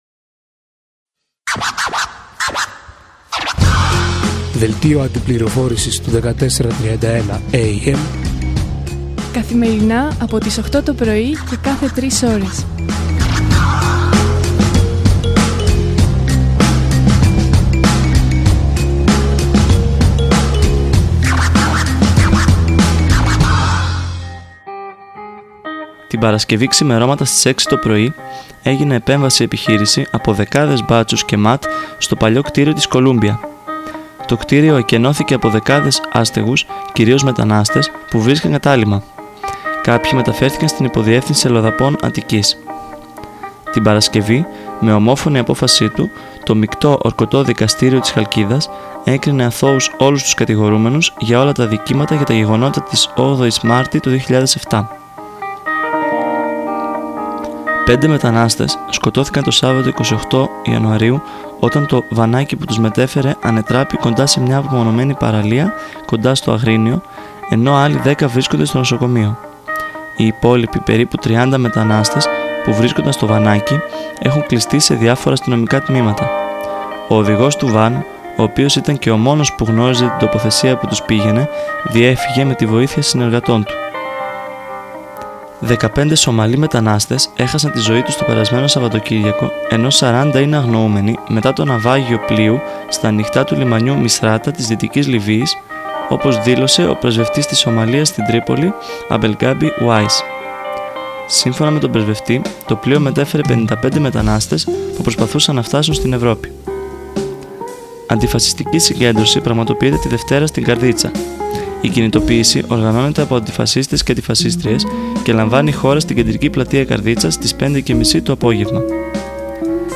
Δελτίο Αντιπληροφόρησης